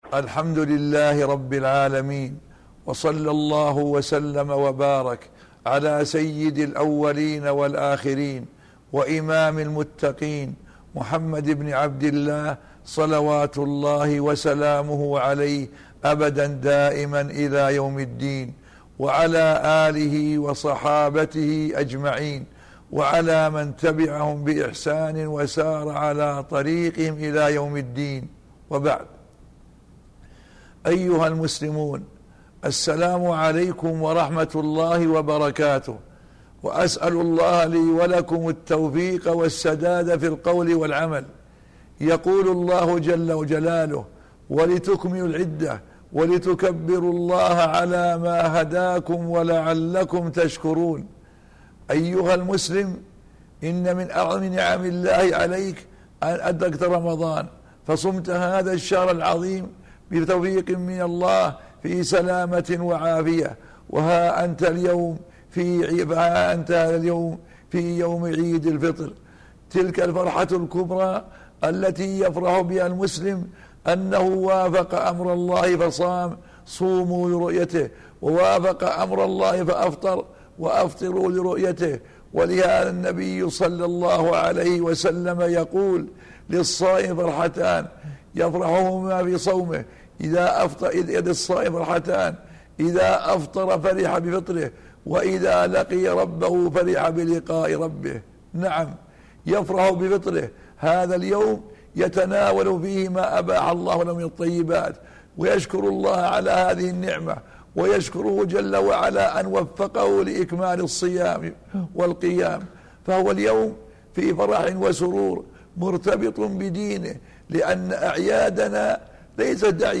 كلمة بمناسبة عيدالفطر المبارك 1434هـ